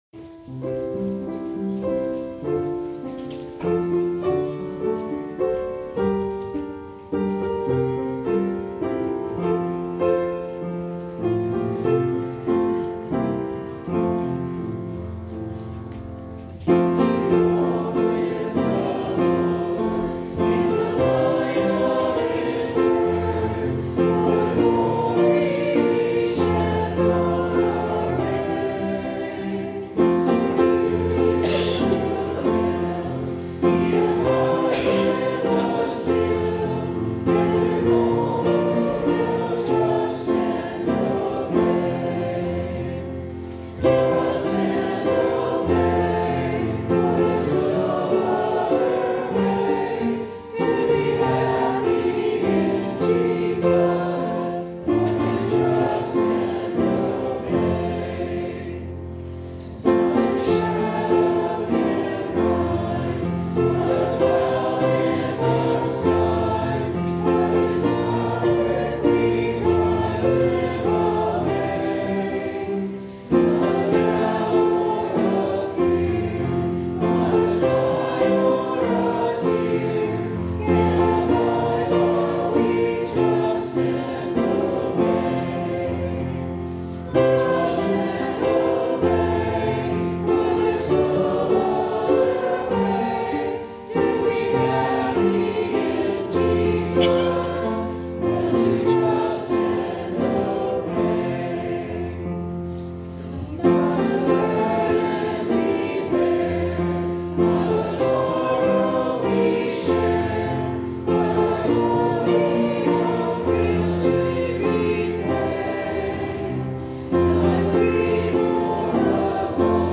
Sending Hymn "Trust and Obey"